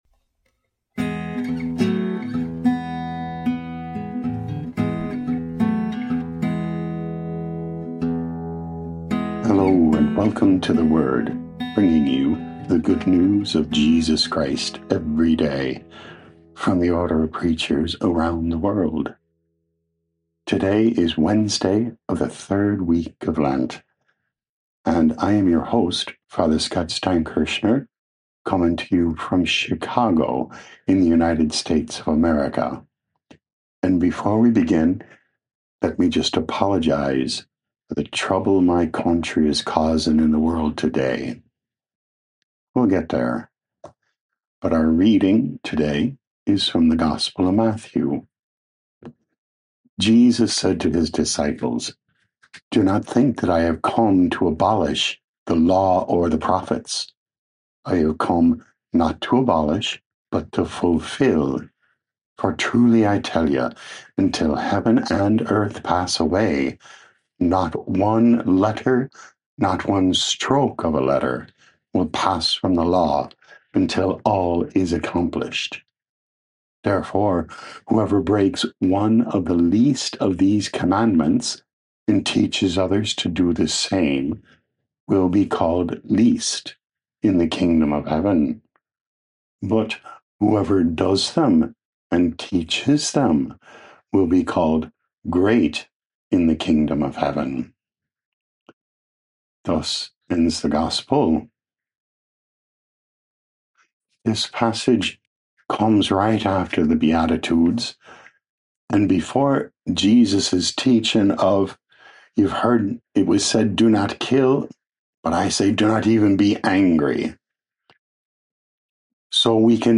OP Preaching